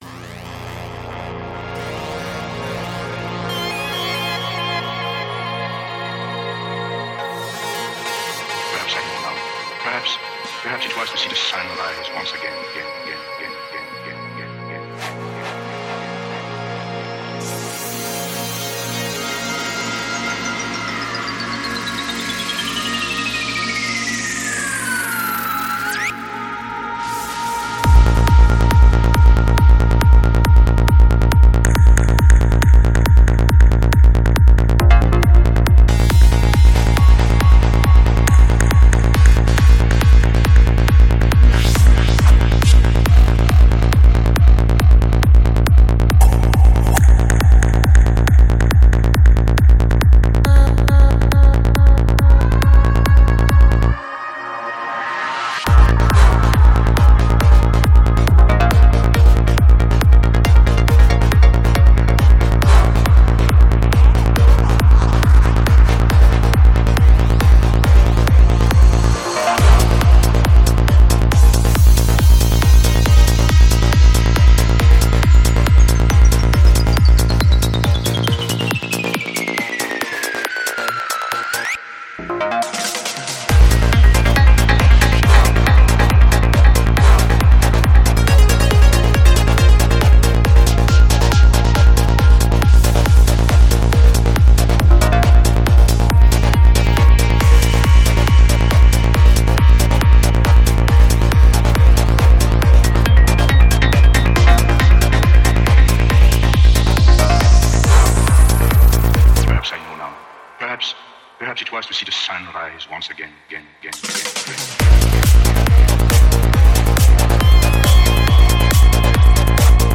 Жанр: Dance
Альбом: Psy-Trance